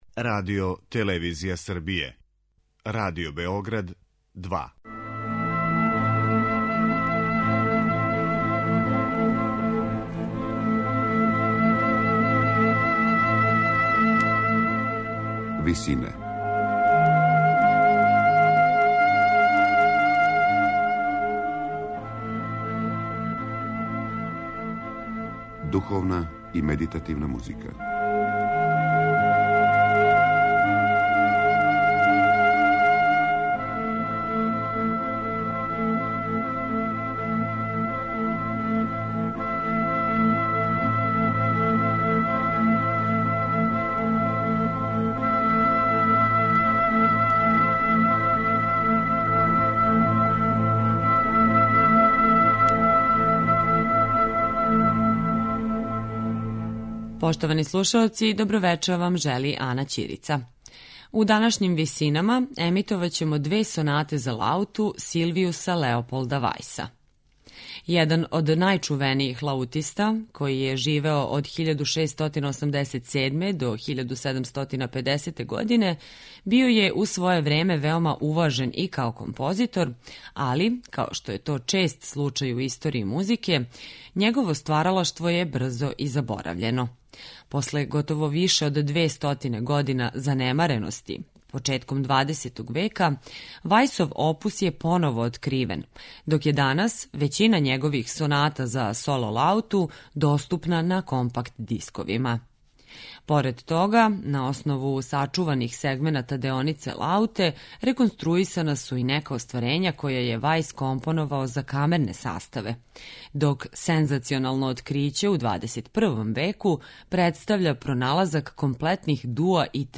Сонате за лауту Силвијуса Леополда Вајса